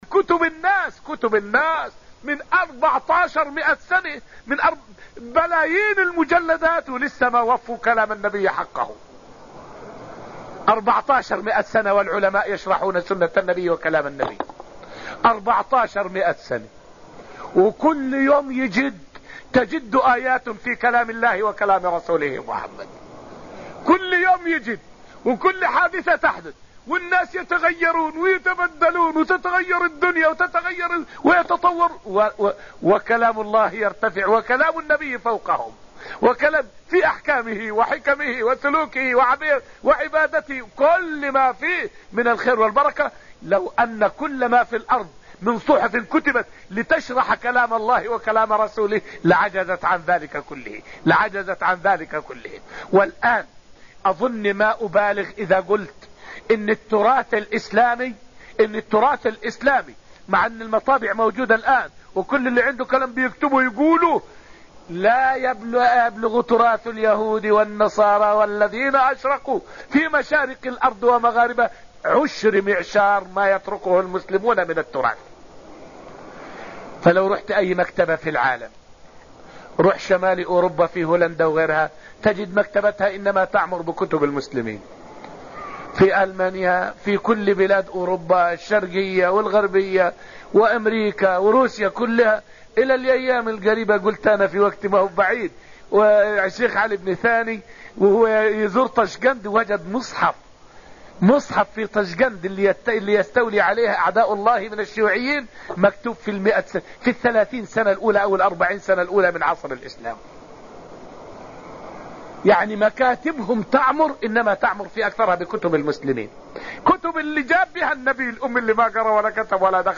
فائدة من الدرس الرابع من دروس تفسير سورة الذاريات والتي ألقيت في المسجد النبوي الشريف حول استفادة الأمم من التراث العلمي والحضاري للمسلمين.